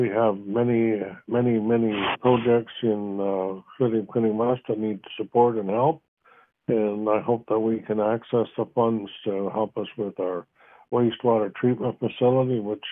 Mayor of Quinte West Jim Harrison.